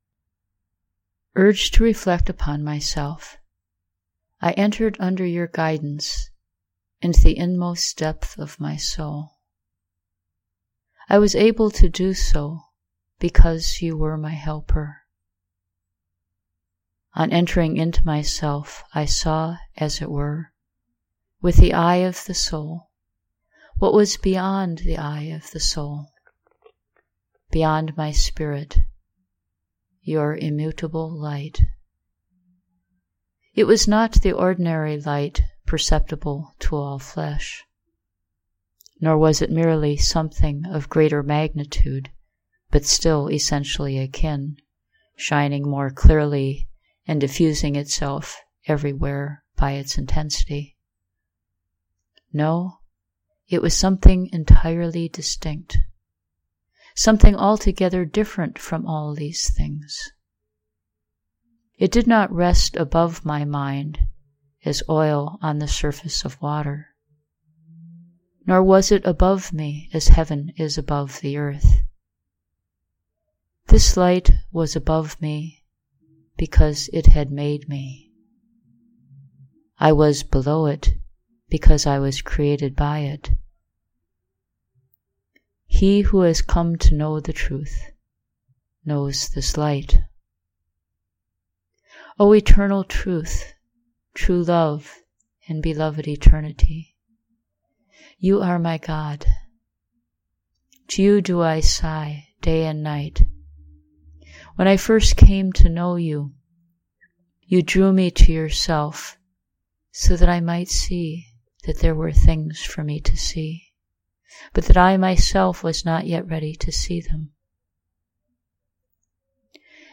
This morning, I wanted to read the words in my own voice.